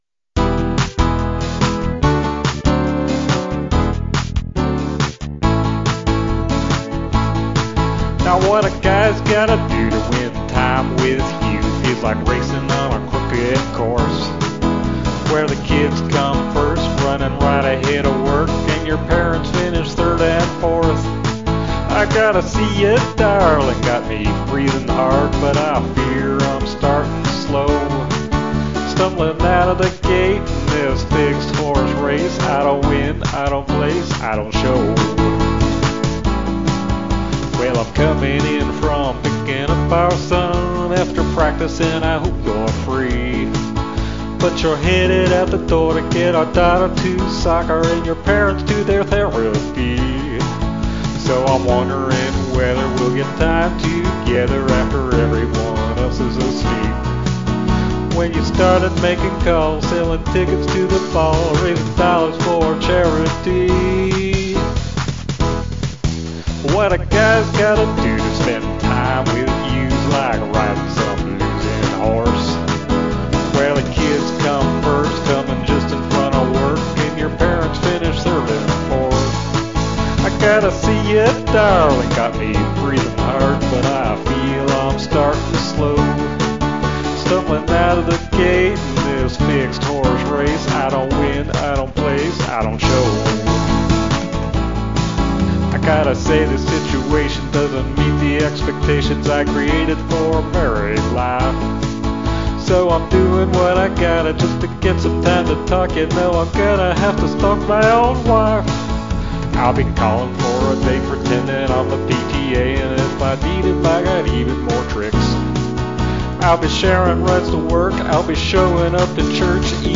uptempo rock country, male voice